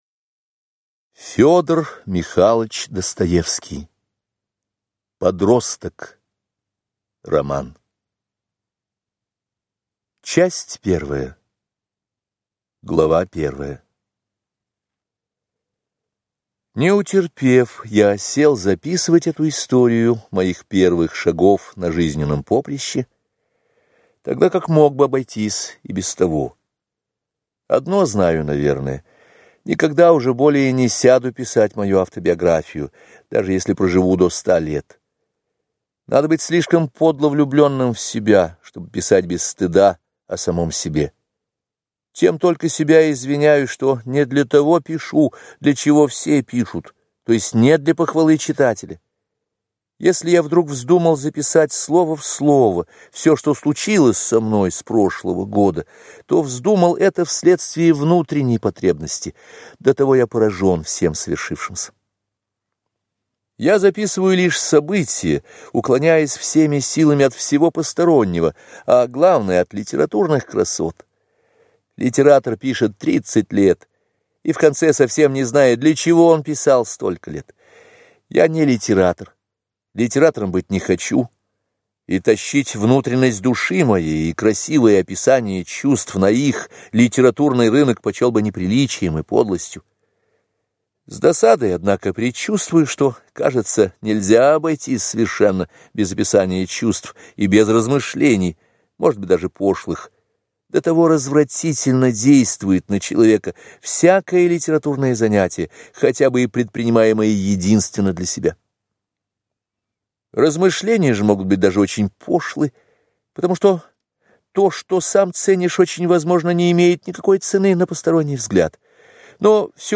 Аудиокнига Подросток | Библиотека аудиокниг